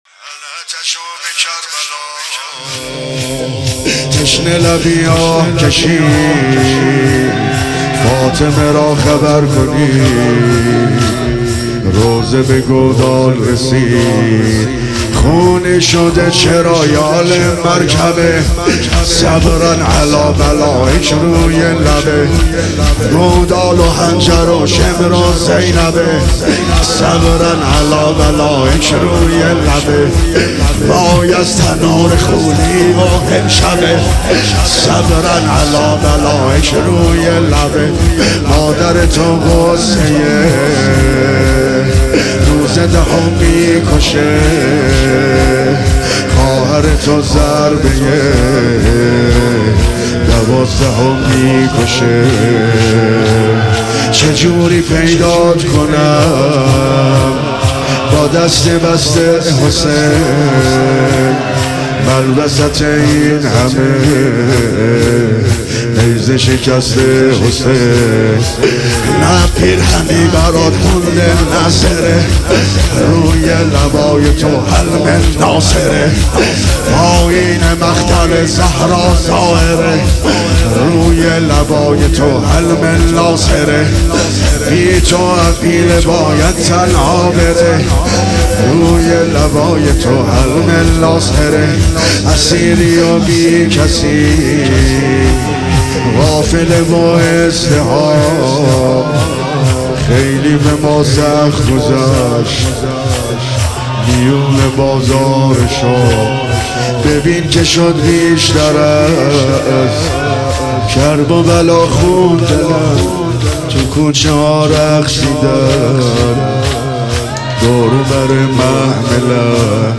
حسینیه کربلا اندرزگو | شهادت امام صادق علیه السلام 1400